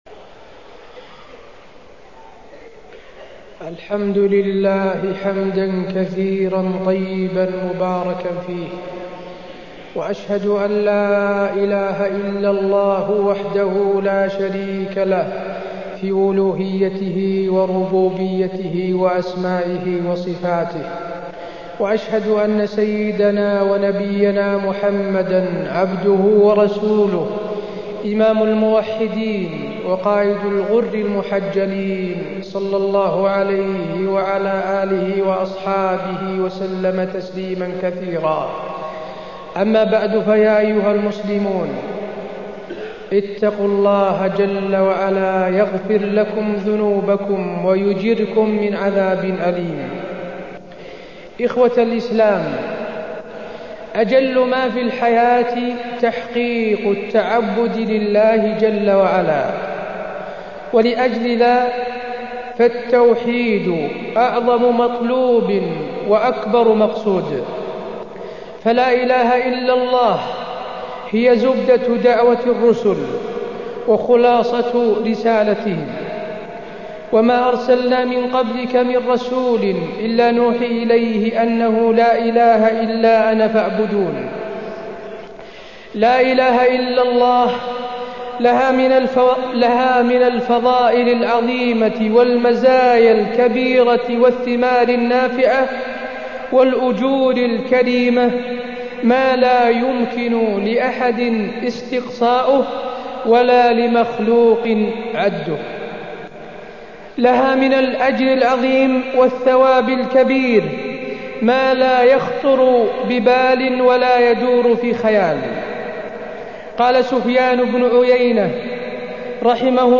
تاريخ النشر ٢٦ ذو القعدة ١٤٢٥ هـ المكان: المسجد النبوي الشيخ: فضيلة الشيخ د. حسين بن عبدالعزيز آل الشيخ فضيلة الشيخ د. حسين بن عبدالعزيز آل الشيخ معنى لا إله إلا الله